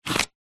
Звуки запикивания мата
Звук пип короткий для запикивания мата 1 секунда